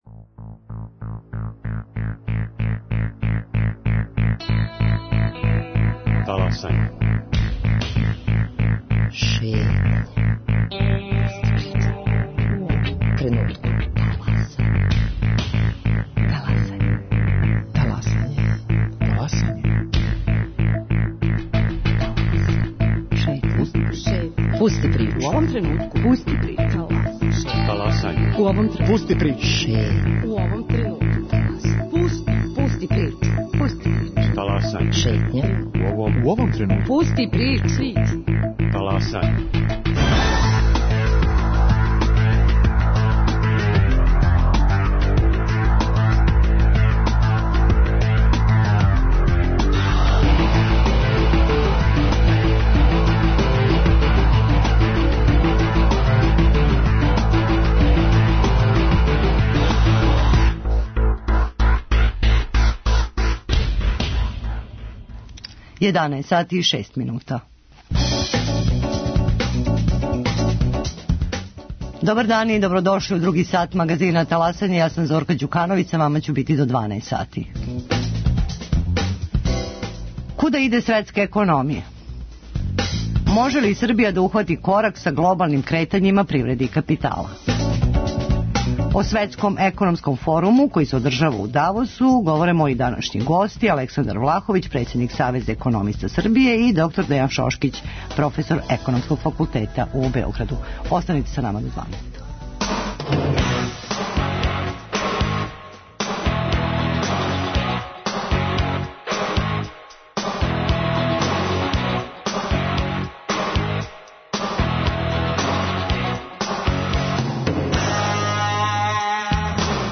О Светском економском форуму који се одржава у Давосу говоре гости